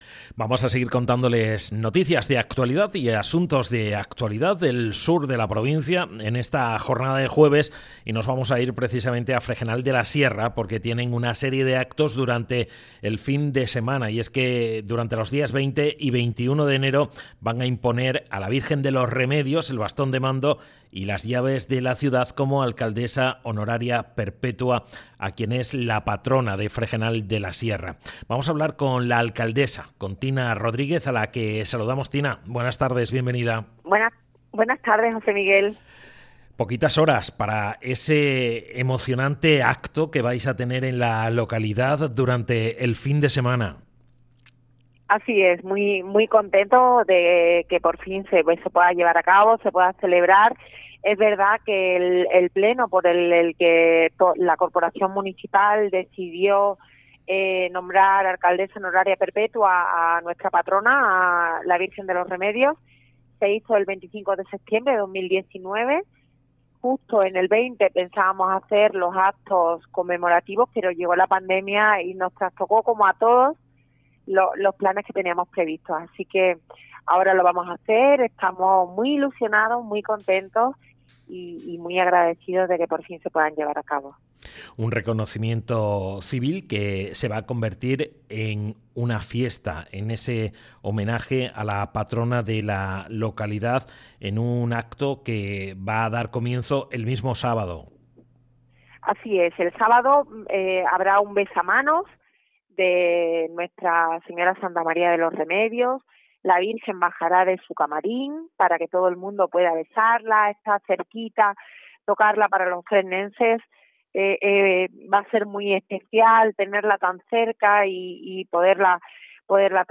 La alcaldesa de la localidad, Agustina Rodríguez, ha contado en Onda Cero el programa de actividades de este reconocimiento civil a la Patrona de Fregenal de la Sierra.